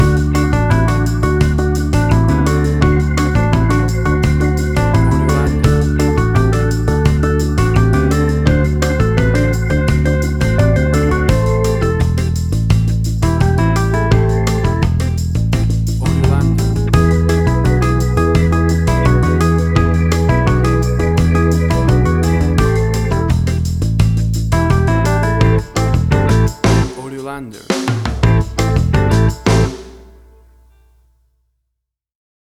Tempo (BPM): 85